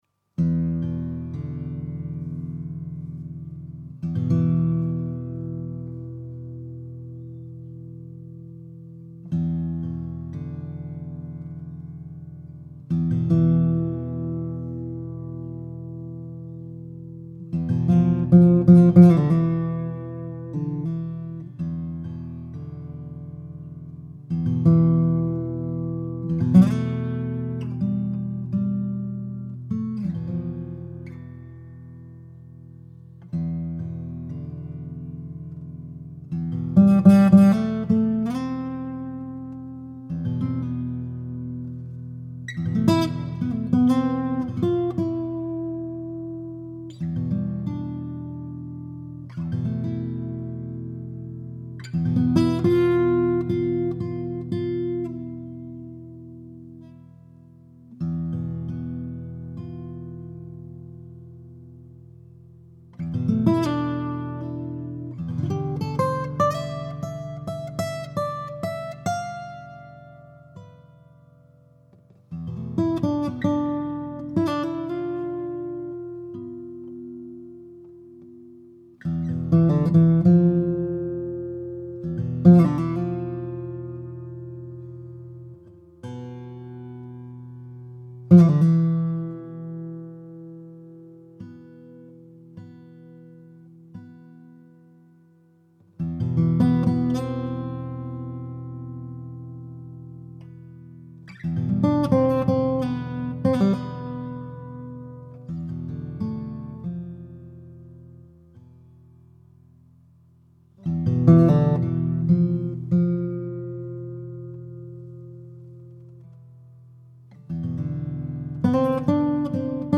Information Three Bluesy-Jazzy improvisations on three Martin guitars. Recorded simply with a condenser mic straight in front of each guitar (only a touch of reverb added, nothing else). There is no different processing for each track so any sonic differences are caused solely by the differences between the guitars.
Guitar